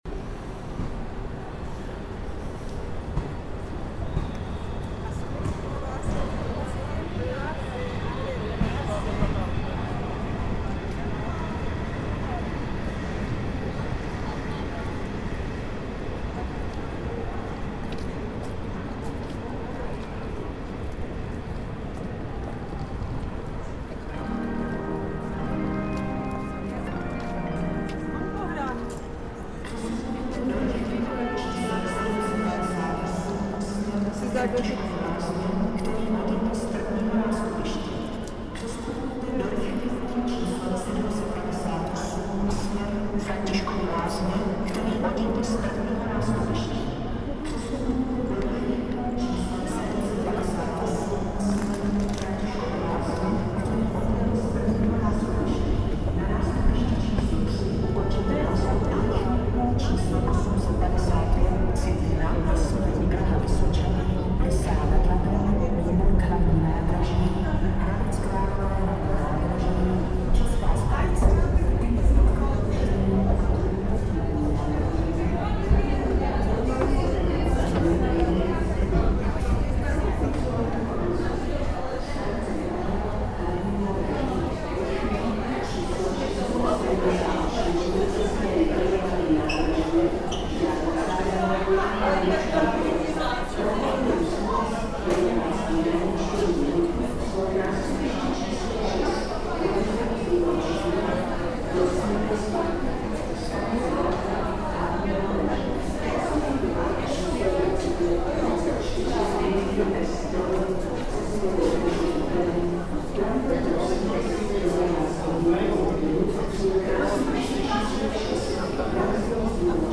Walking from the peron, through the passage towards the main hall.
transportation interiors people railway